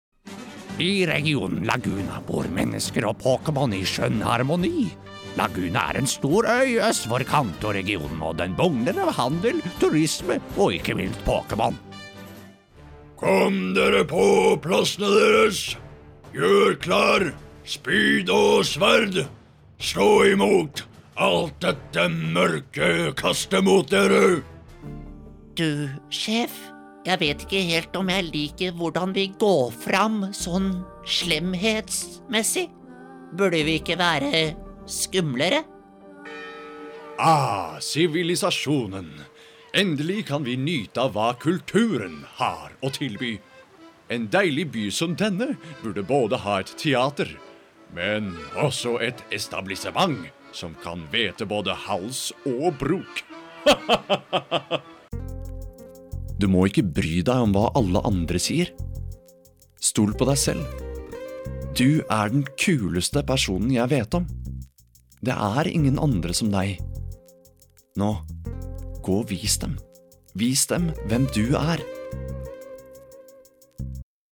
Kommerzielle Demo
Animation
Mic: Shure SM7B + Trtion Audio Fethead Filter
BaritonTiefNiedrig
VertrauenswürdigWarmZuverlässigFreundlich